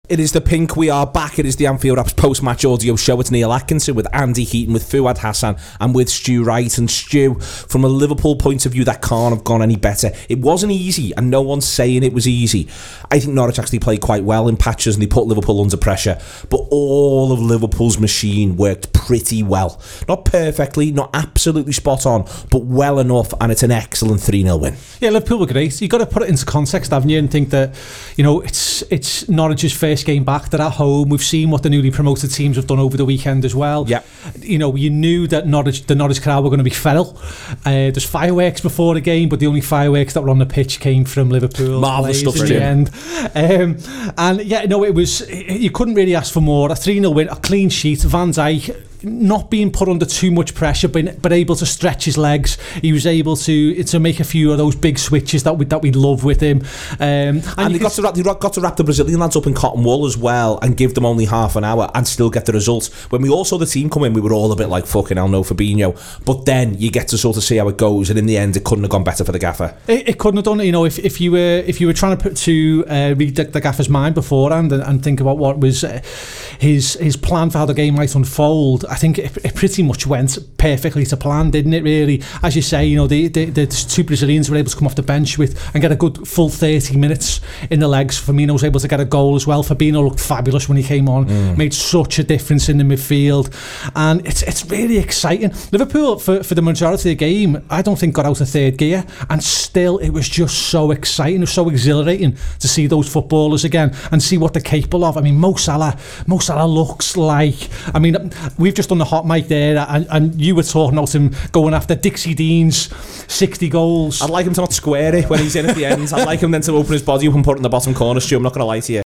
Post-match reaction podcast after Norwich City 0 Liverpool 3 at Carrow Road